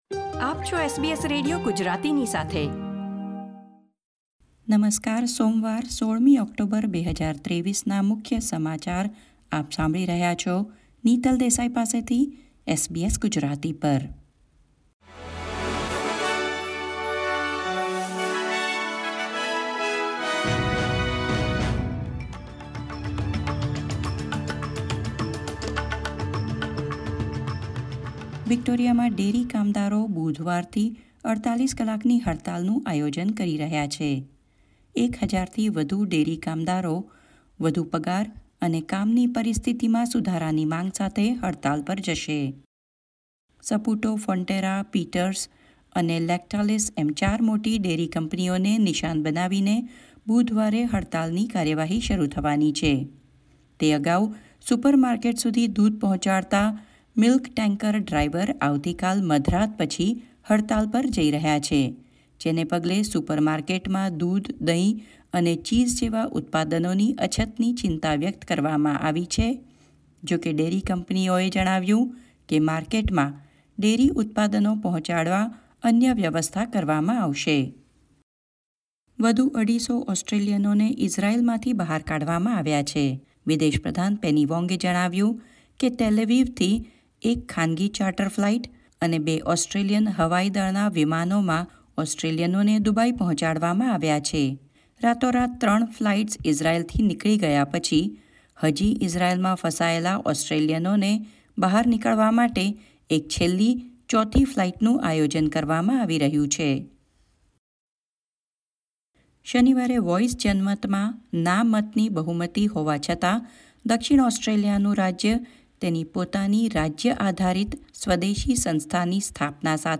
SBS Gujarati News Bulletin 16 October 2023